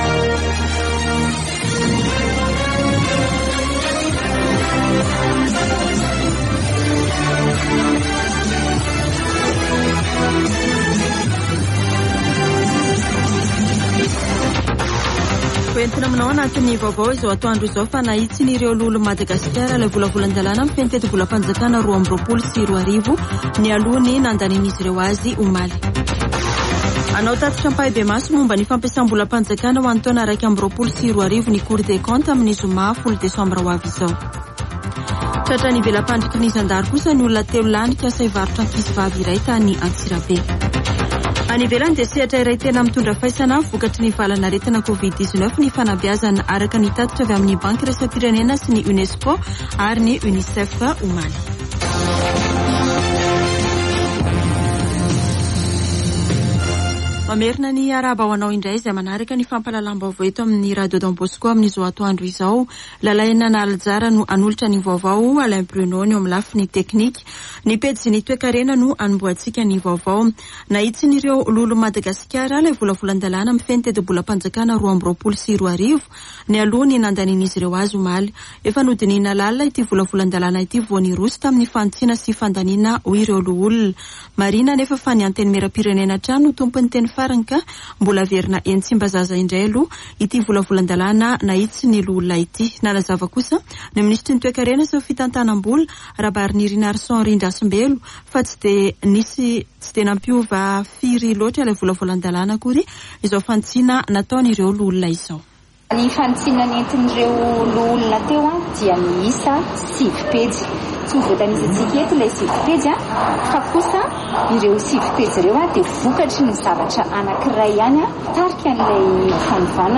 [Vaovao antoandro] Alarobia 08 desambra 2021